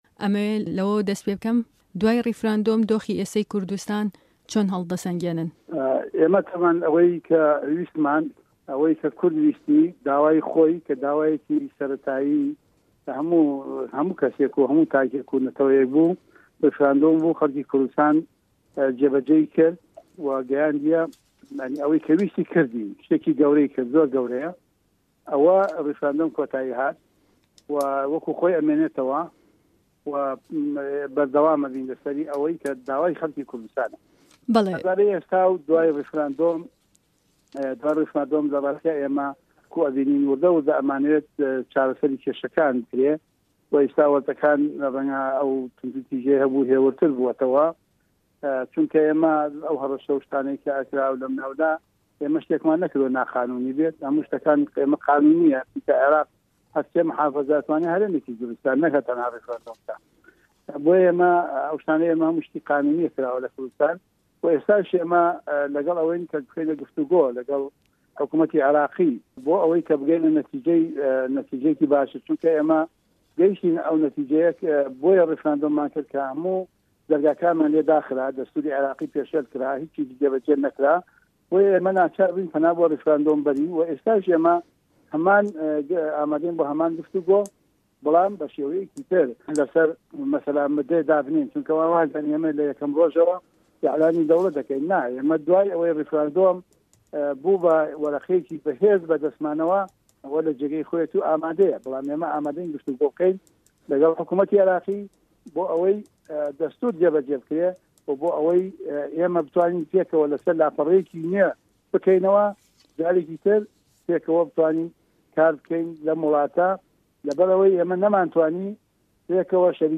وتووێژ لەگەڵ محه‌مه‌دی حاجی مه‌حمود